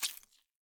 Footsteps
slime1.ogg